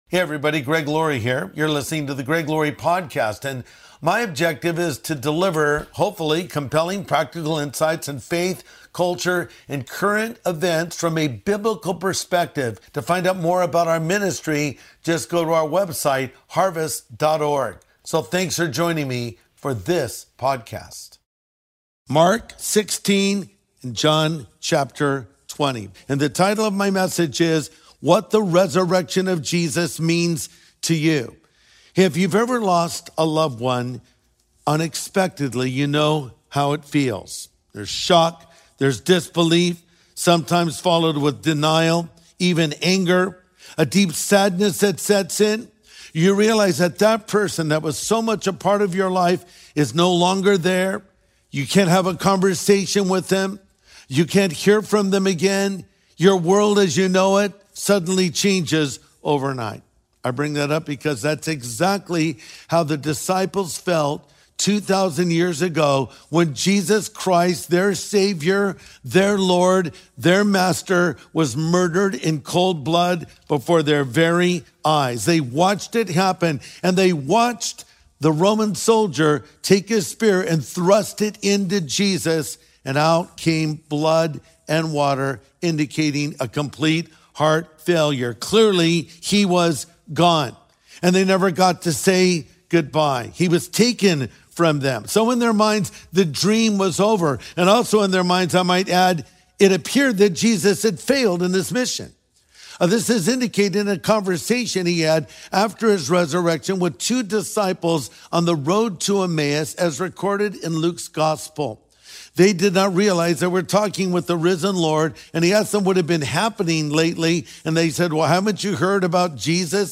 What the Resurrection of Jesus Means to You | Sunday Message
In this Sunday episode, Pastor Greg Laurie explores the defining event of our faith.